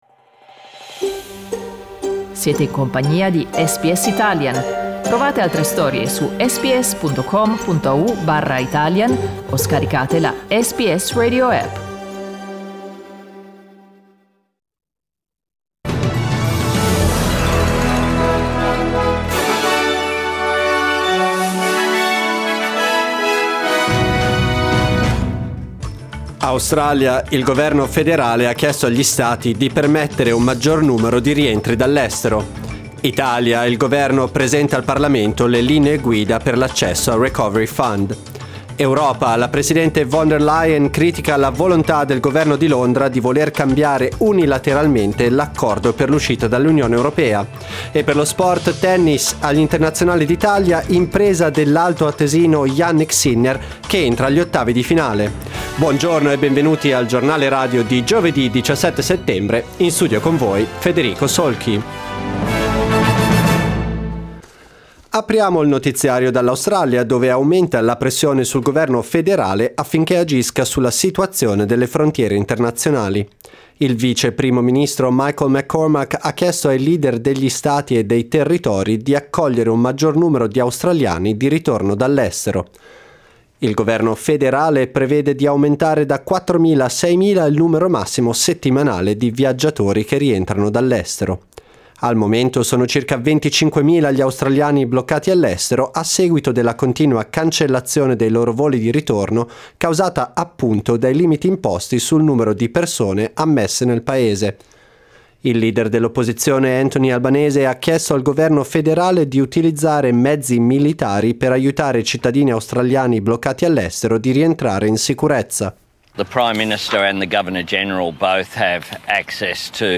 Our news bulletin (in Italian)